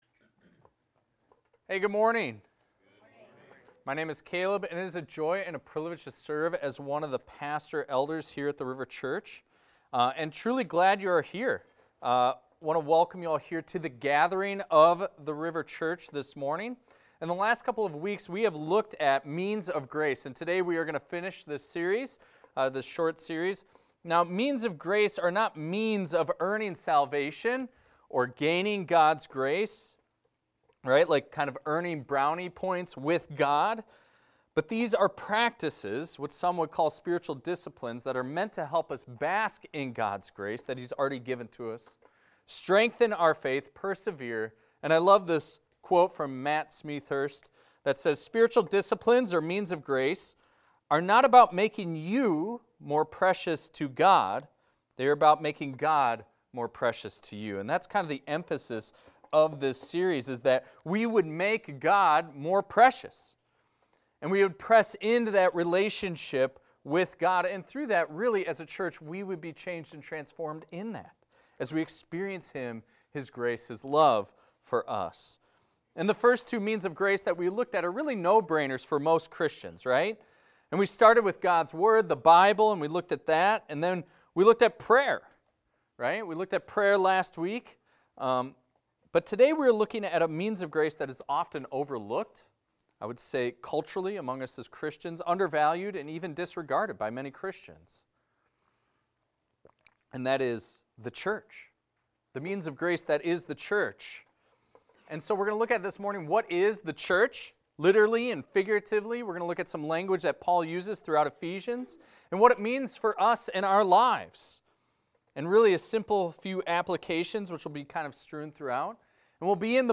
This is a recording of a sermon titled, "The Gift of the Church."